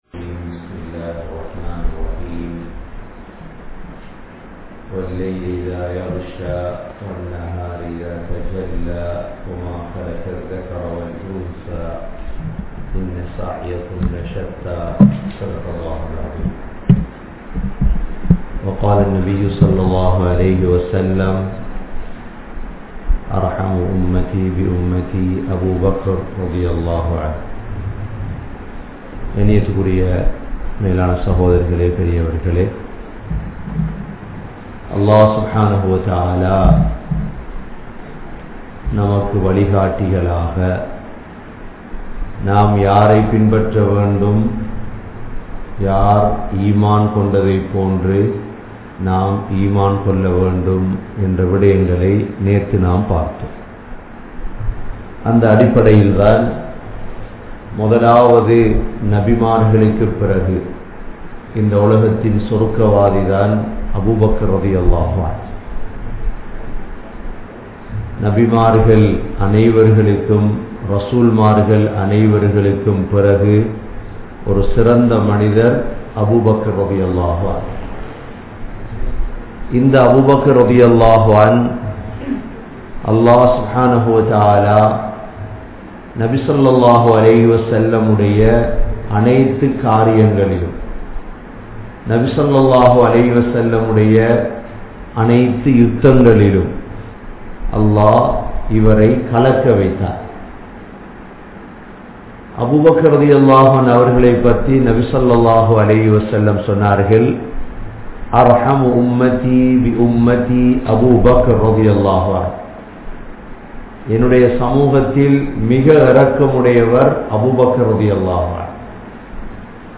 Abu Bakr(Rali) (Part 01) | Audio Bayans | All Ceylon Muslim Youth Community | Addalaichenai
Canada, Toronto, Thaqwa Masjidh